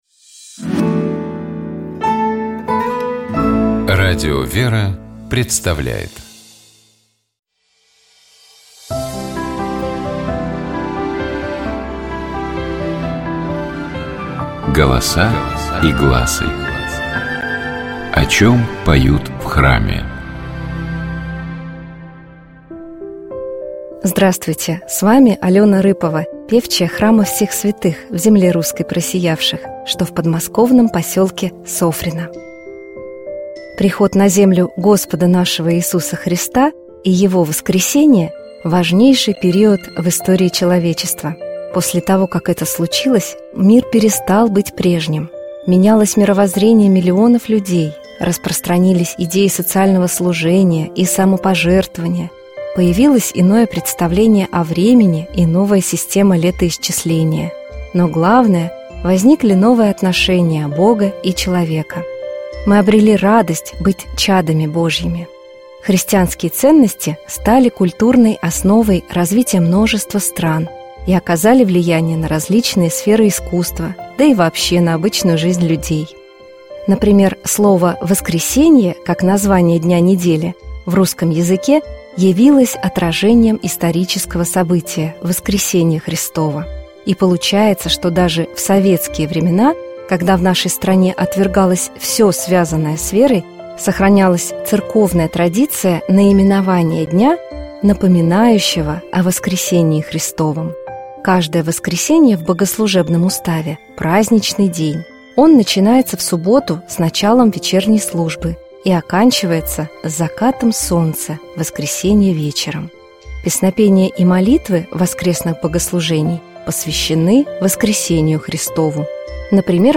Golosa-i-glasy-Svetluju-voskresenija-propoved-voskresnyj-tropar-4-go-glasa.mp3